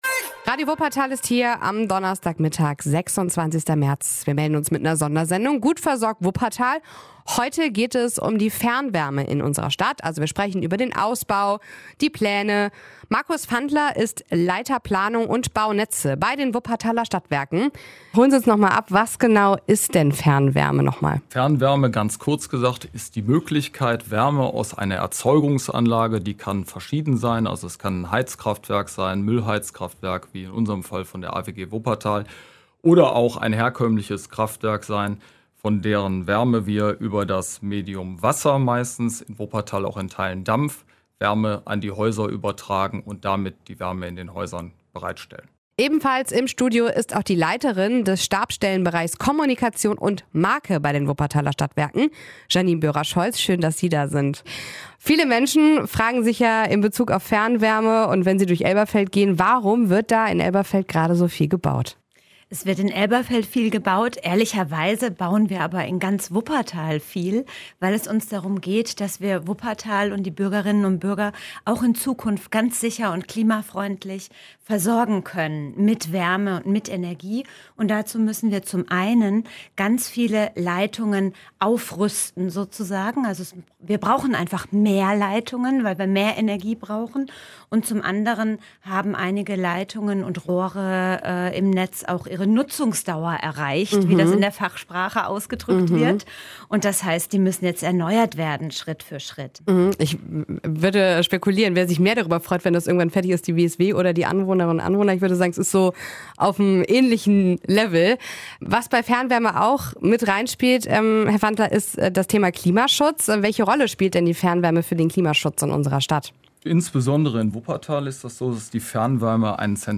Das ist Thema in der Sondersendung WSW Gut versorgt in Wuppertal.